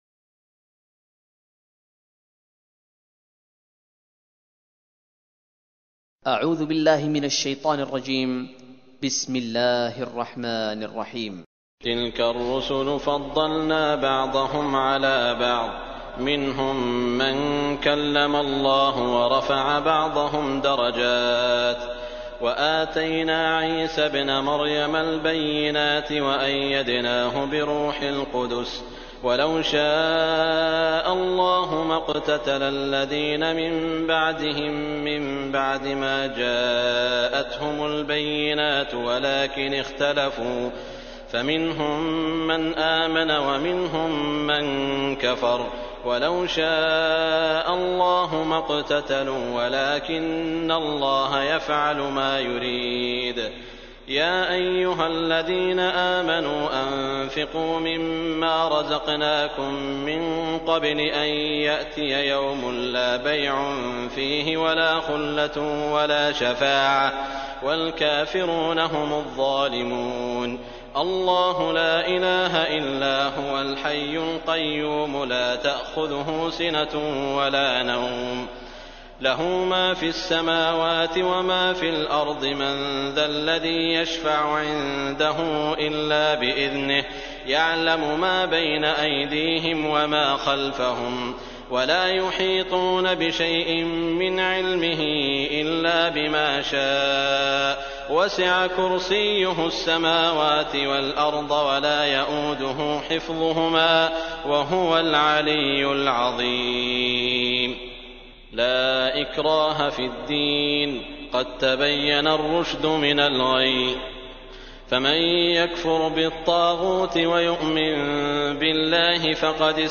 سعود الشریم - ترتیل جزء سوم قرآن / ترجمه فارسی
دانلود جزء سوم قرآن به همراه ترجمه فارسی بصوت سعود الشریم
دانلود تلاوت قرآن با صدای سعود شریم